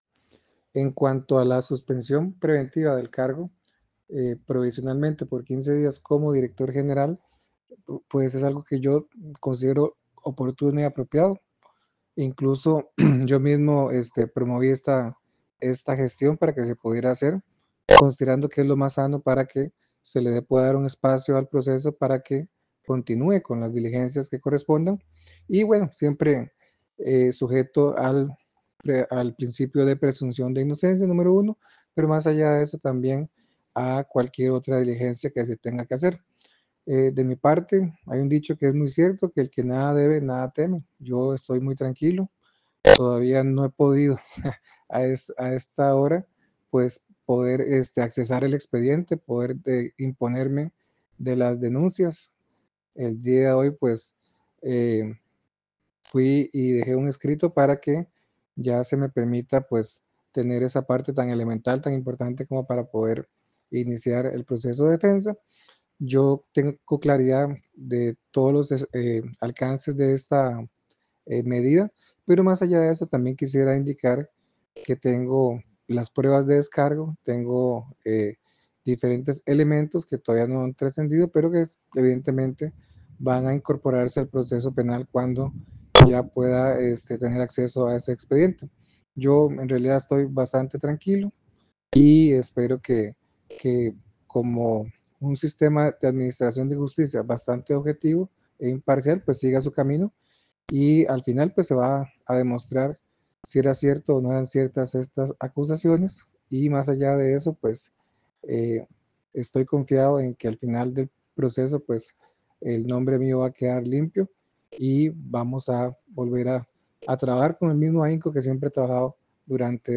Tal como se puede escuchar en el audio del pronunciamiento de Zúñiga, él considera que la suspensión es adecuada y es una medida sana para facilitar el avance de la investigación.